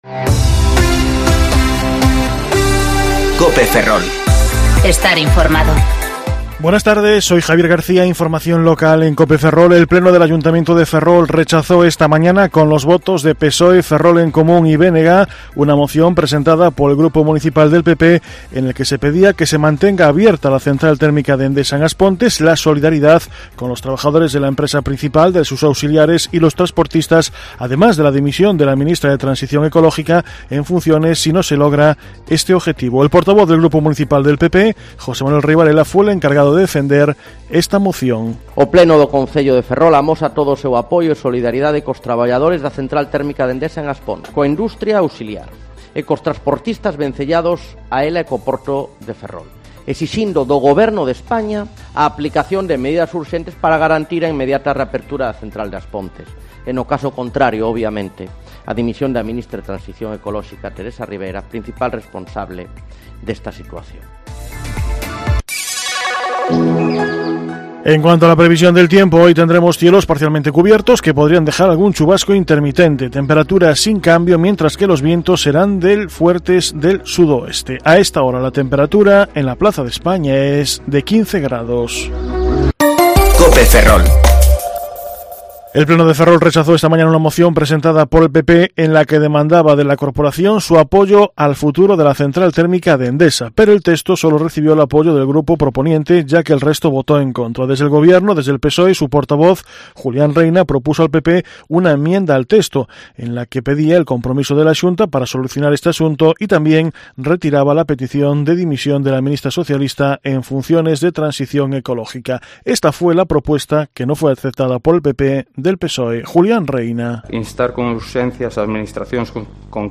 Informativo Mediodía Cope Ferrol 15/10/2019 (De 14.20 a 14.30 horas)